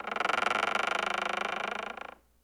Index of /90_sSampleCDs/E-MU Producer Series Vol. 3 – Hollywood Sound Effects/Human & Animal/Falling Branches
CREAK-L.wav